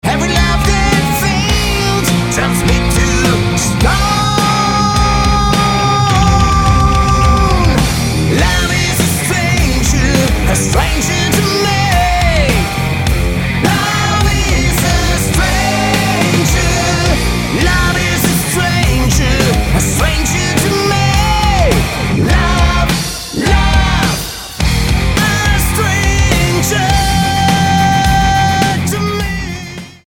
Lead Vocals
Guitar
Drums